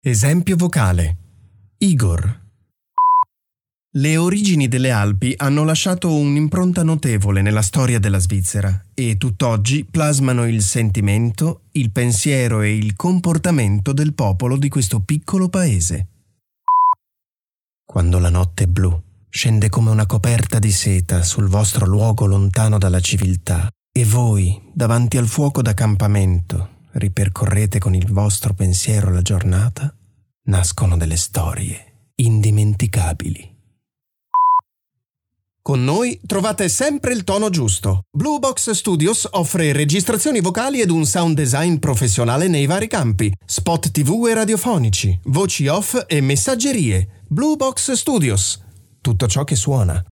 OFF-Voice Italienisch (CH)